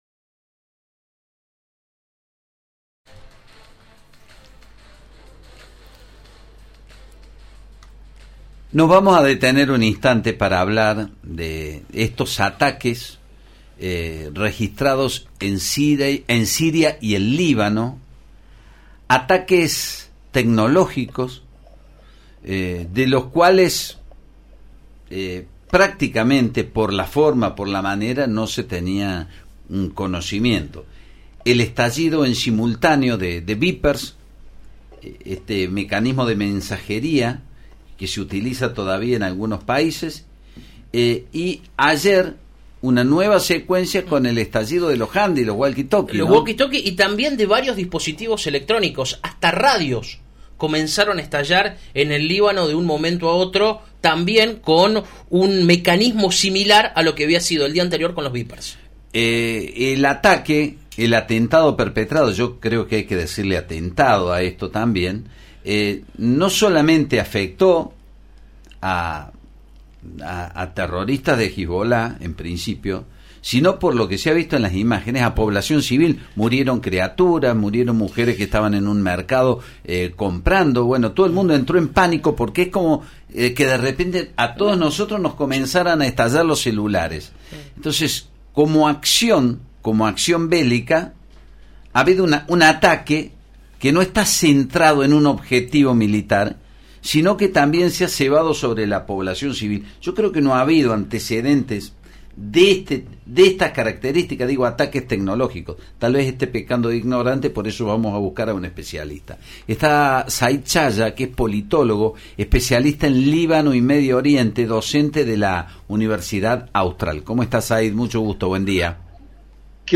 Entrevista
en radio Mitre de Córdoba.